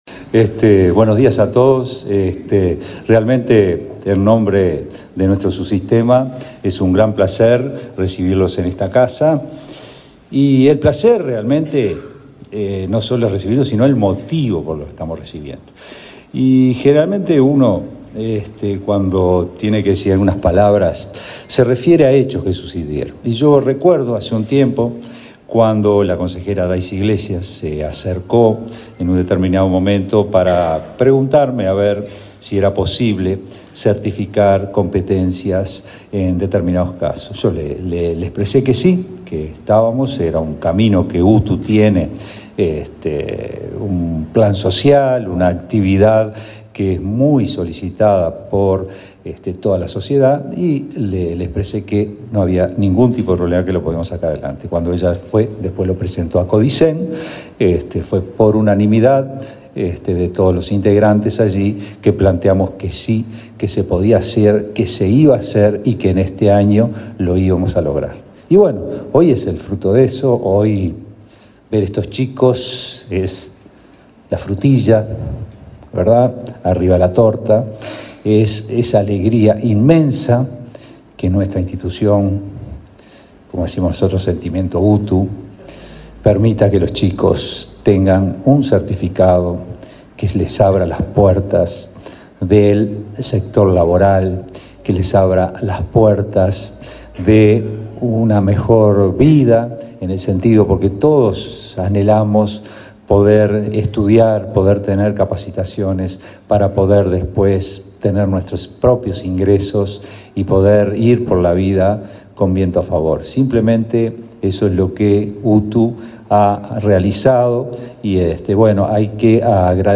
Palabras del director general de UTU y el presidente de la ANEP
Este martes 13, en Montevideo, el director general de la UTU, Juan Pereyra, y el presidente de la ANEP, Robert Silva, participaron en la entrega de